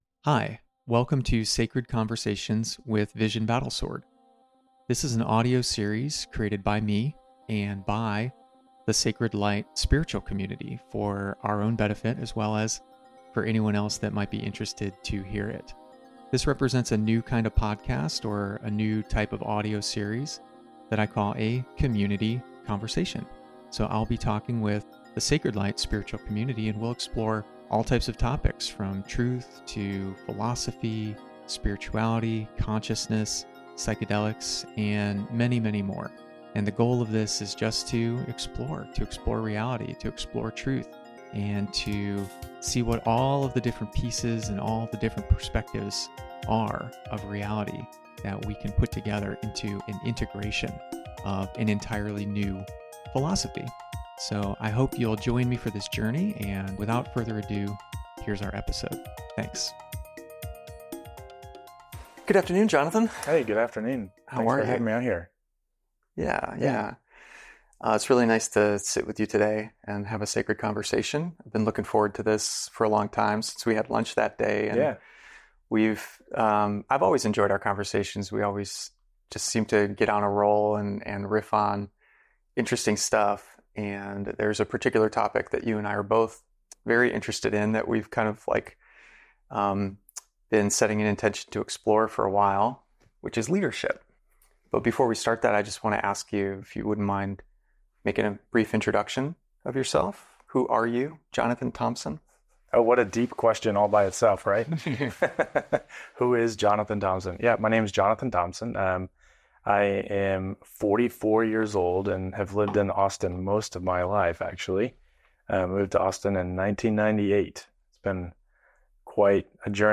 conversation07-leadership.mp3